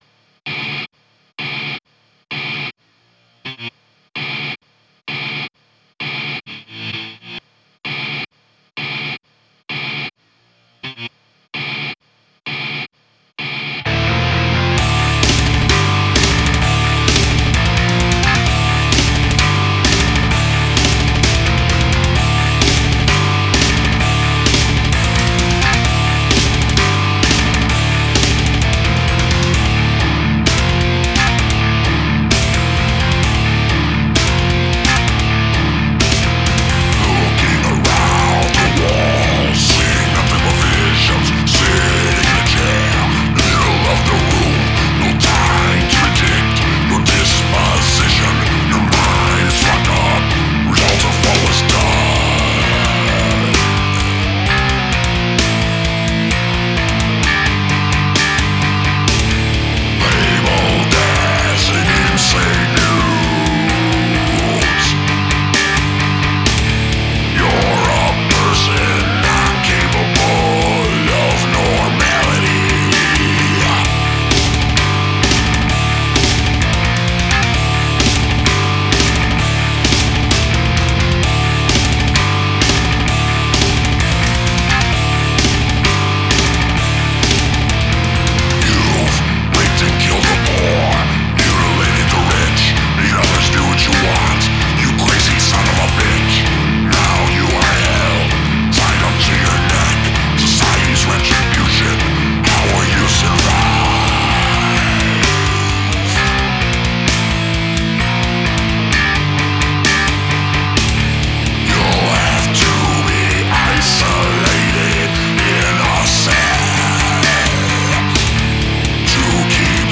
Call it metal, call it sick, it is what it is.